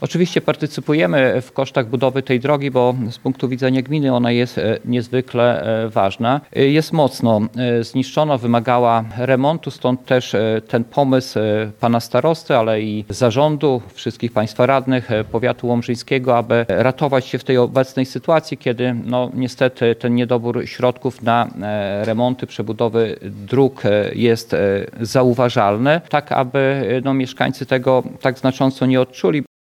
Wójt gminy Śniadowo Rafał Pstrągowski przypomniał, że to już czwarta inwestycja na terenie jego gminy w ramach Funduszu Powiatowo-Gminnego.